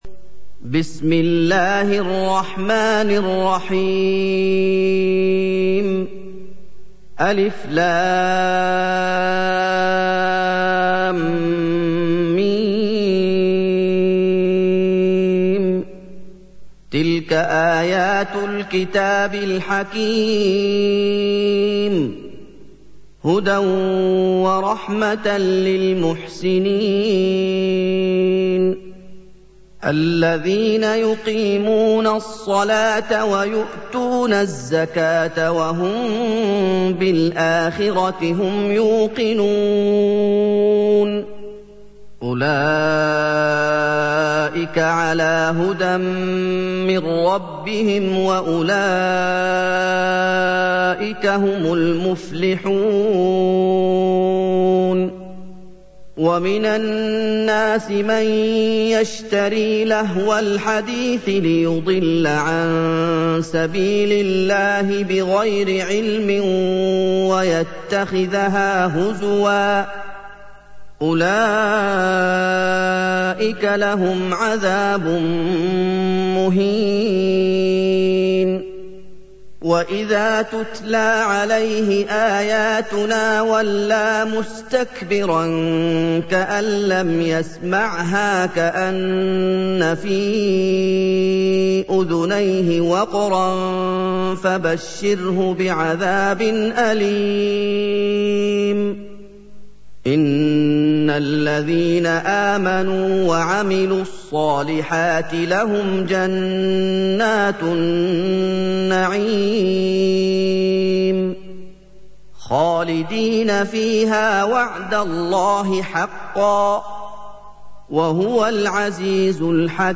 سُورَةُ لُقۡمَانَ بصوت الشيخ محمد ايوب